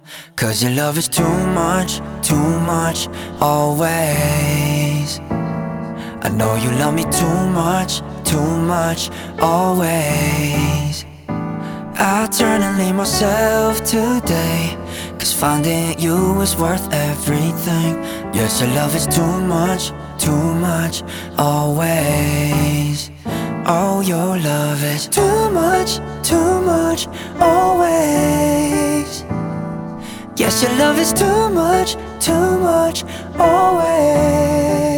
Скачать припев
K-Pop Pop
2025-04-07 Жанр: Поп музыка Длительность